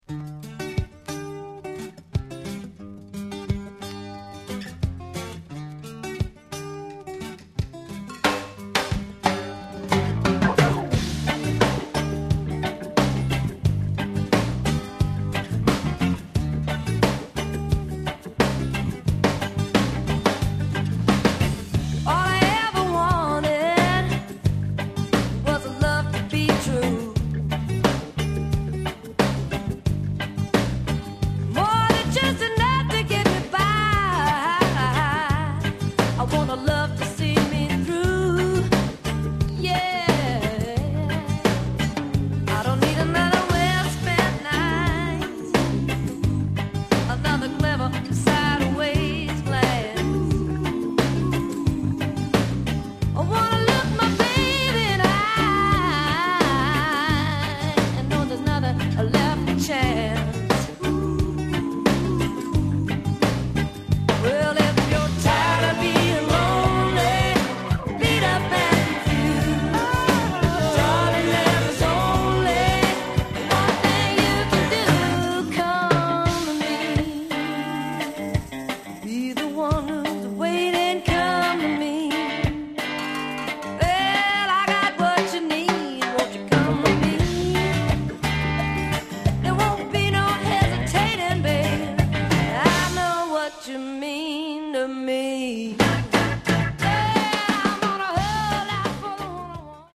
an unbelievable slice of funky soul on the flip